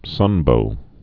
(sŭnbō)